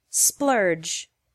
• Listen to the pronunciation